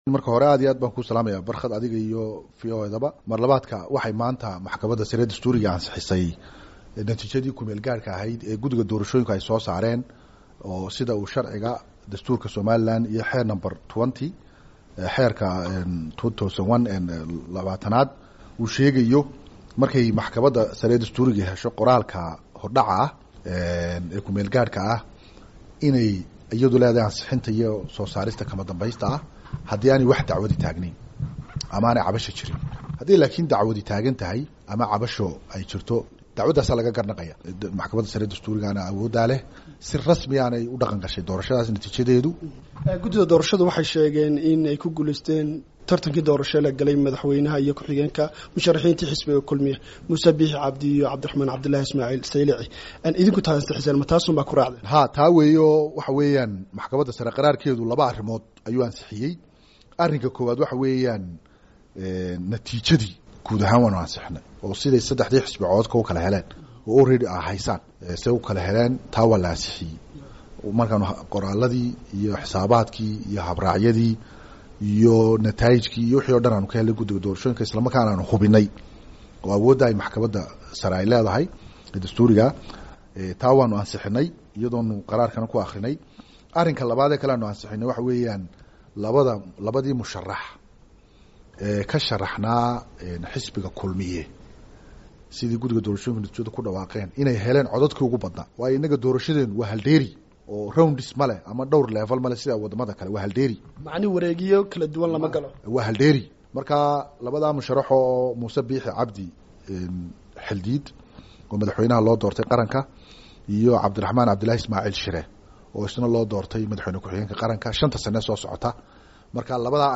Wareysi: Guddoomiyaha maxkamadda sare ee Somaliland